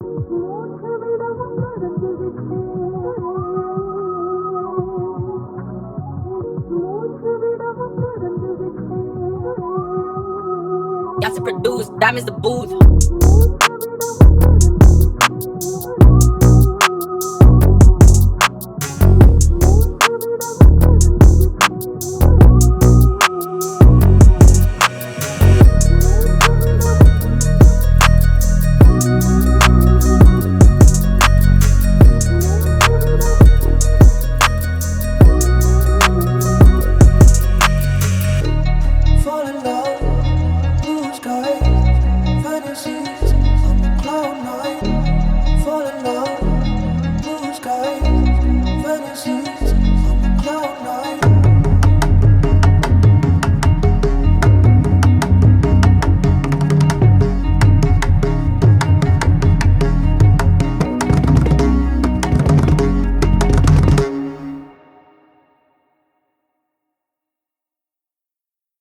Hip Hop Instrumentals